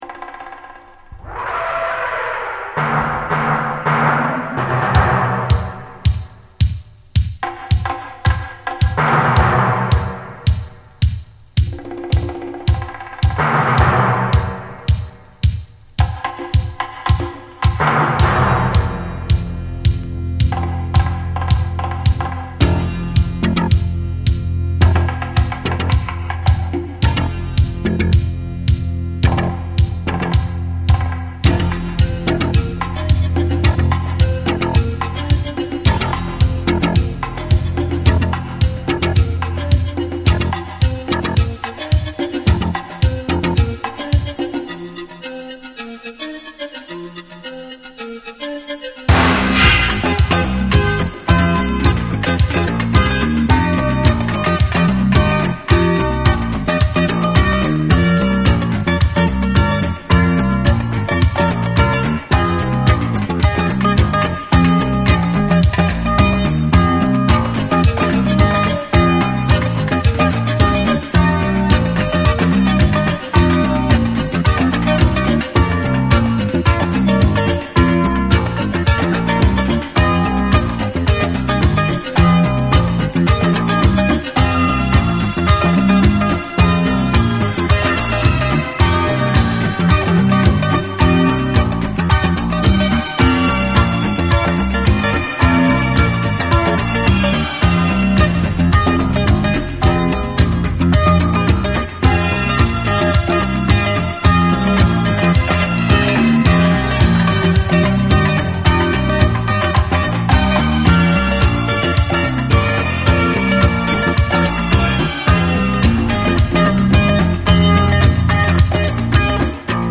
PERCUSSIONS
AFRO MIX